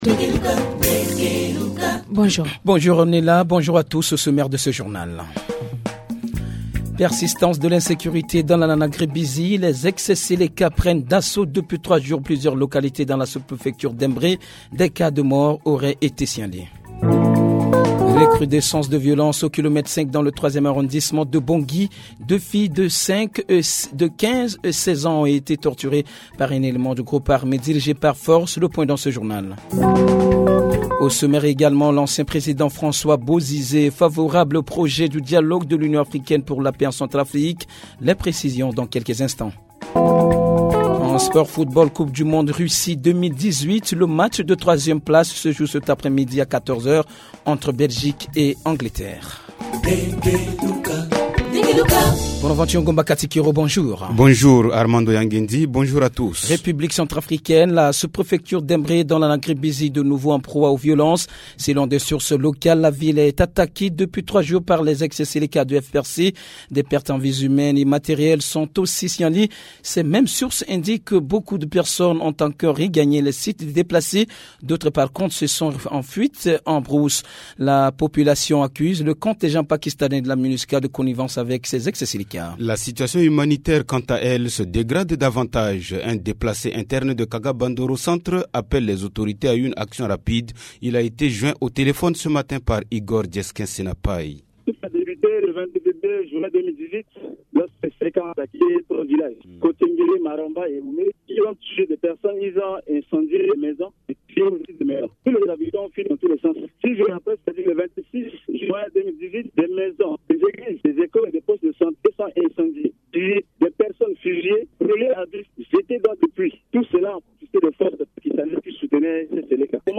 Journal Français